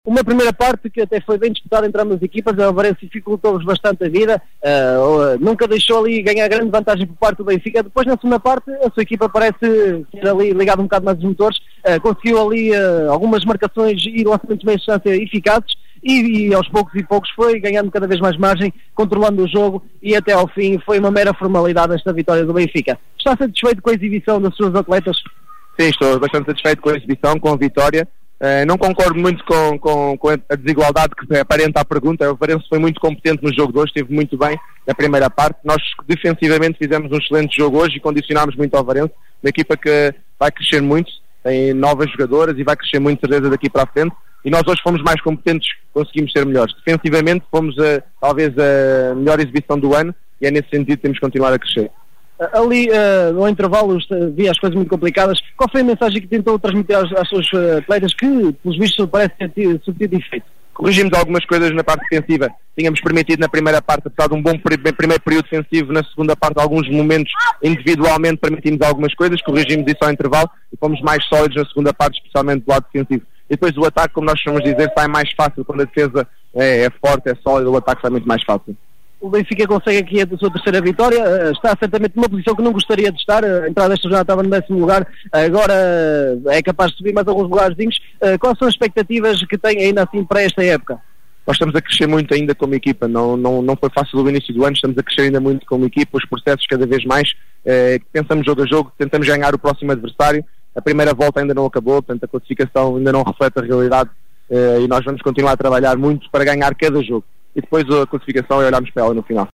Declarações Benfica: